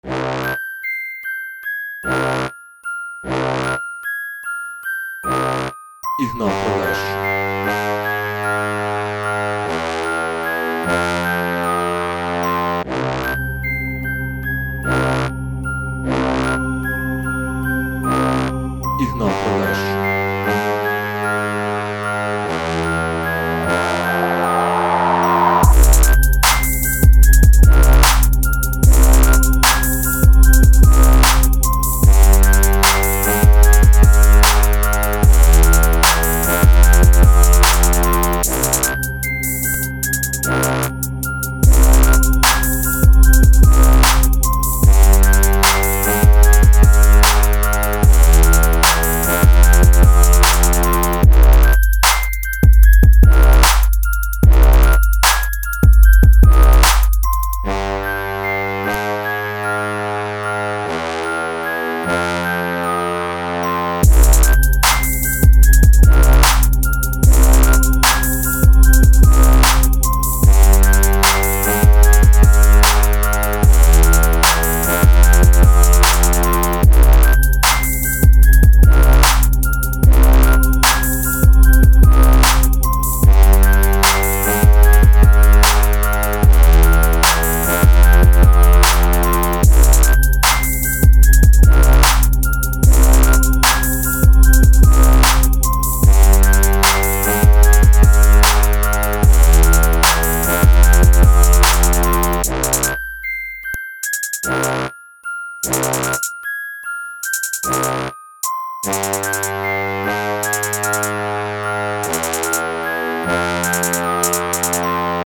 Скачать Минус
Стиль: Rap